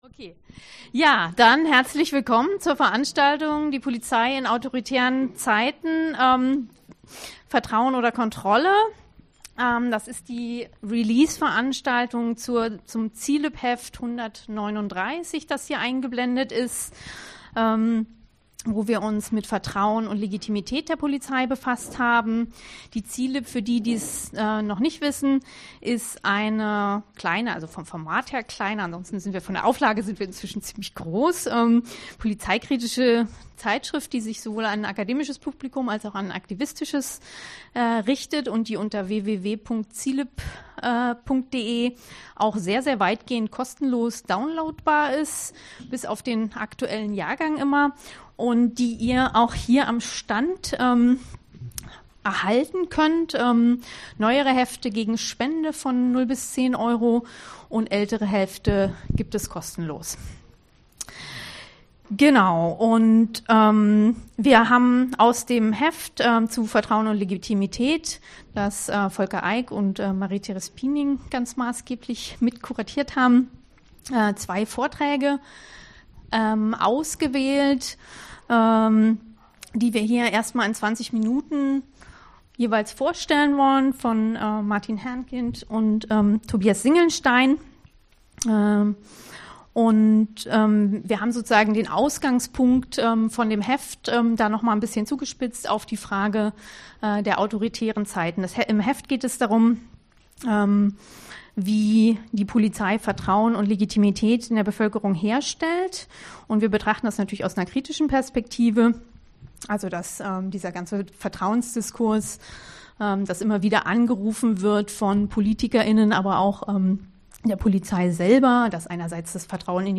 Film and discussion about detention camps for refugees in Poland